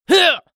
CK被击倒03.wav
CK被击倒03.wav 0:00.00 0:00.56 CK被击倒03.wav WAV · 48 KB · 單聲道 (1ch) 下载文件 本站所有音效均采用 CC0 授权 ，可免费用于商业与个人项目，无需署名。
人声采集素材/男2刺客型/CK被击倒03.wav